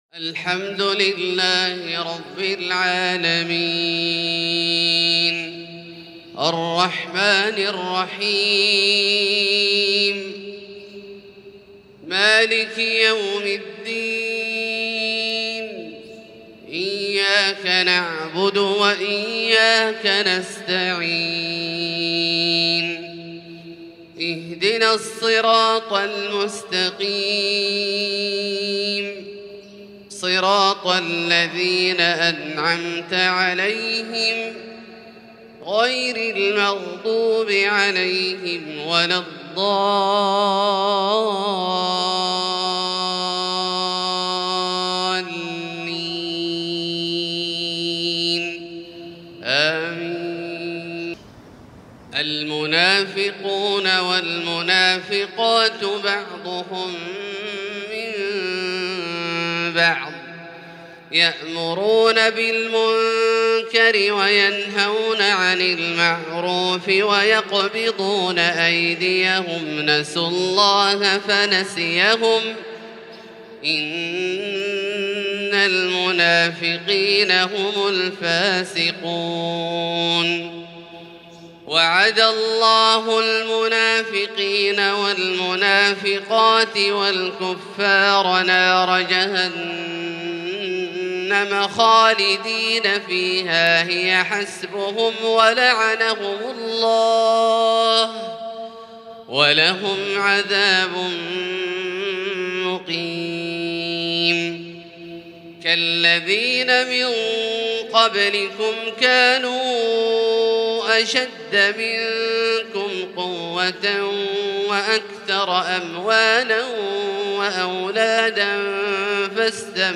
فجر الإثنين 3-4-1443هـ من سورة التوبة | Fajr 8/11/2021 prayer from Surat At-Tawbah > 1443 🕋 > الفروض - تلاوات الحرمين